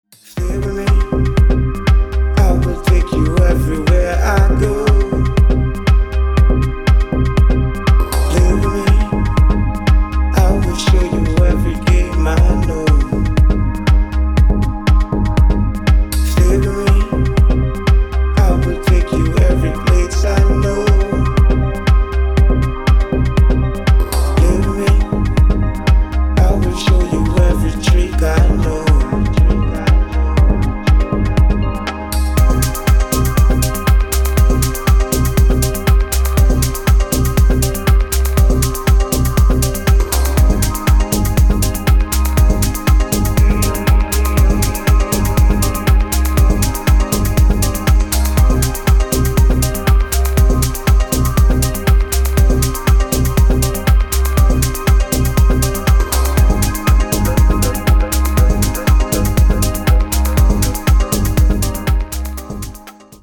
• Качество: 256, Stereo
мужской вокал
deep house
атмосферные
dance
EDM
электронная музыка
спокойные
Tech House
Melodic house